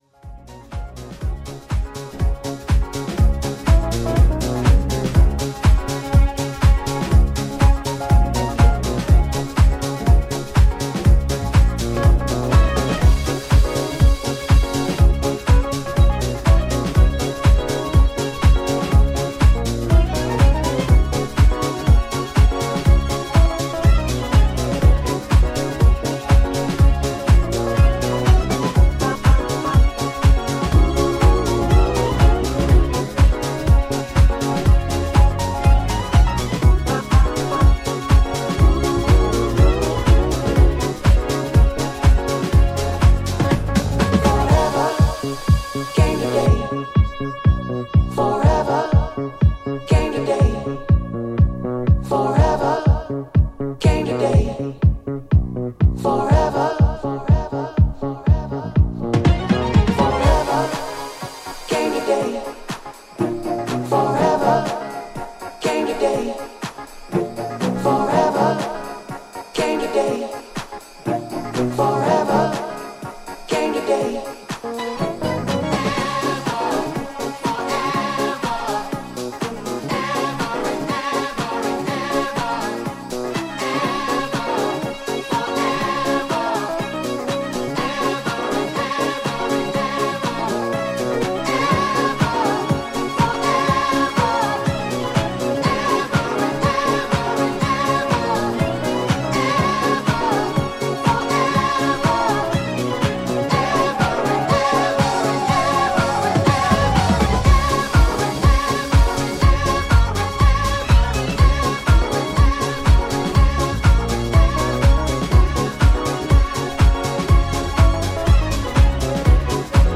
ジャンル(スタイル) DISCO / HOUSE / DEEP HOUSE